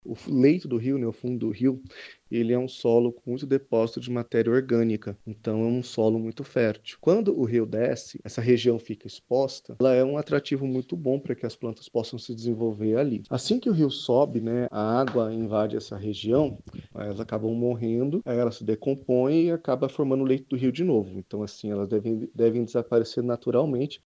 SONORA01_ILHAS-DE-CANARANAS.mp3